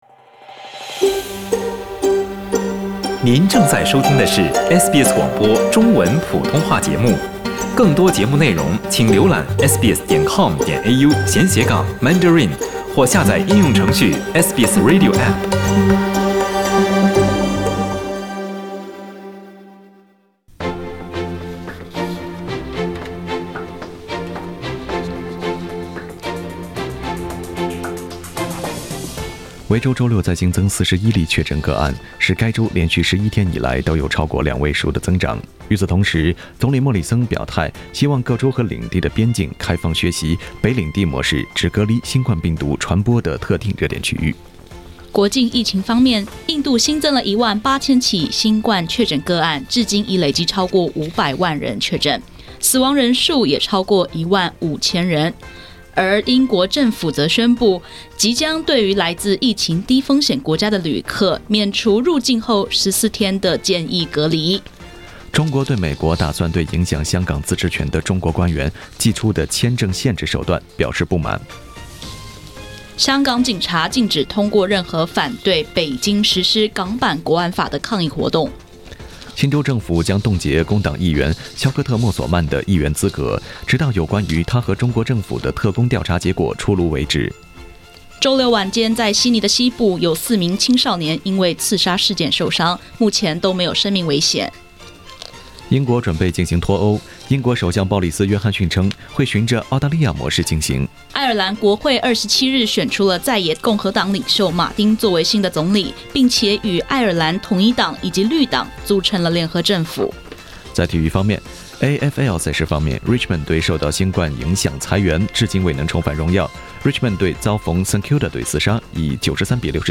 SBS早新闻（6月28日）